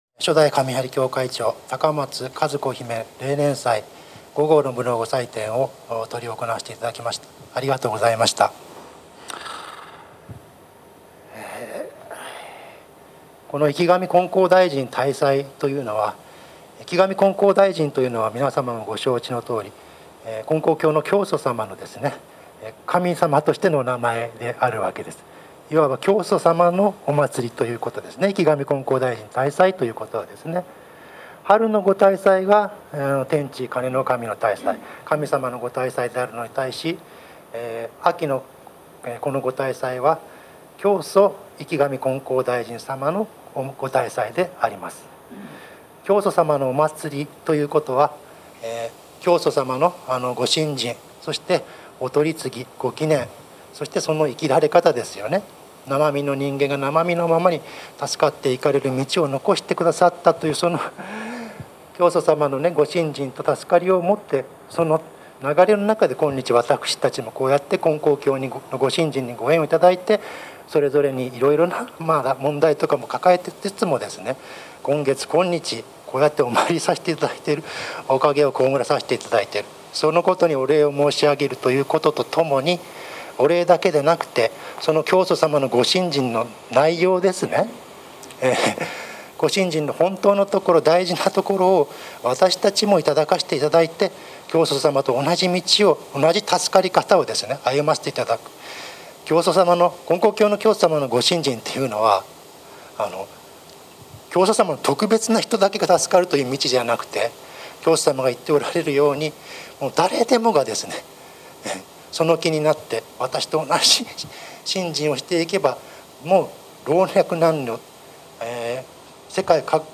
生神金光大神大祭並びに髙松和子姫例年祭教話